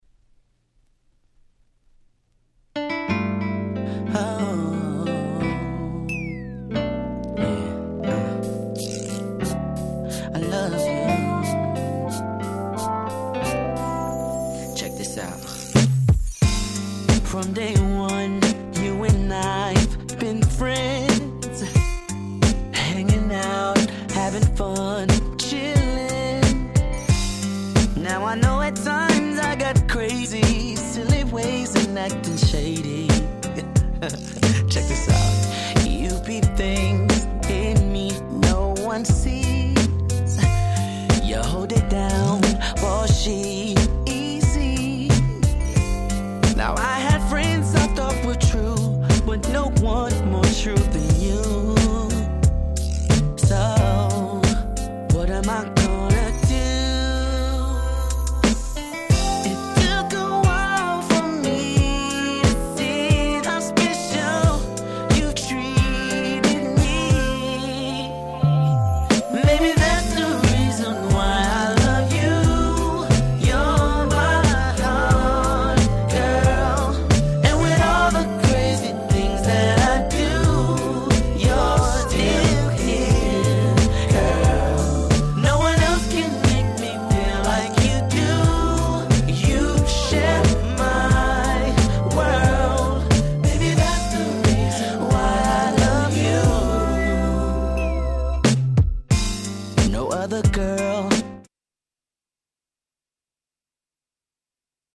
【Media】Vinyl 12'' Single (Promo)